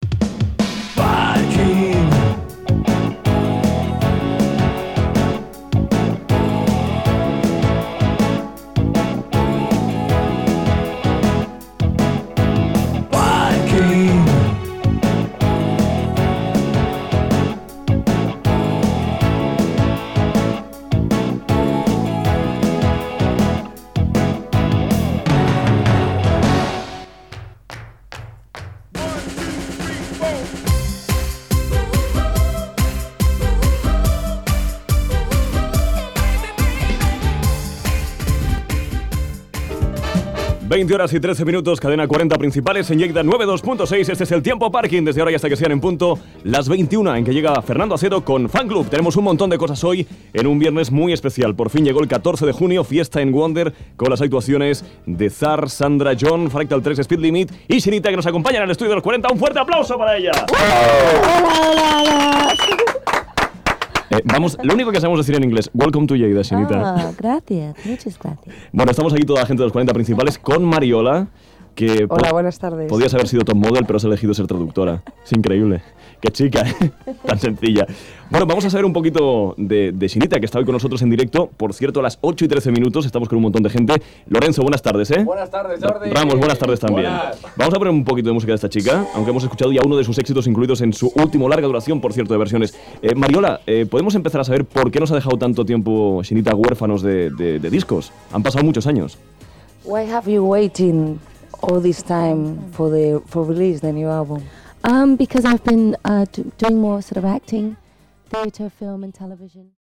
Indicatiu del programa, hora, identificació i presentació de la cantant Sinitta que actua aquell dia a la discoteca Wonder
Musical
FM